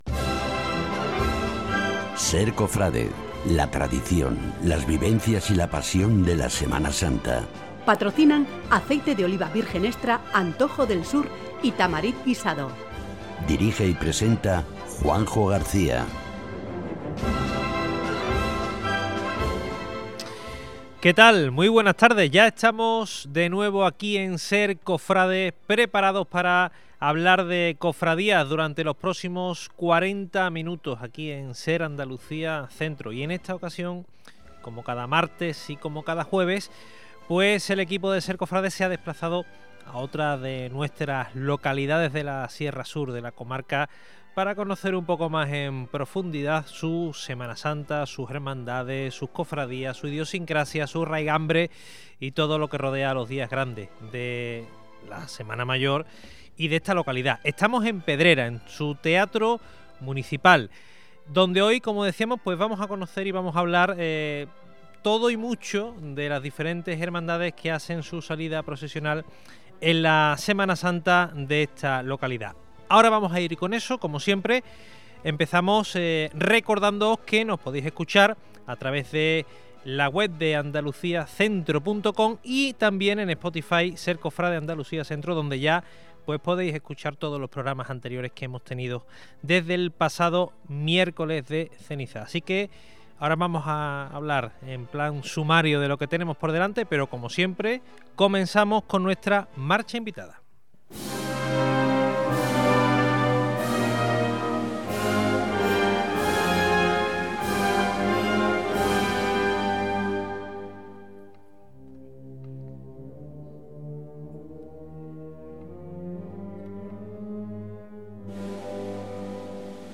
SER COFRADE 030326 PEDRERA Programa especial SER Cofrade emitido desde el Teatro Municipal de Pedrera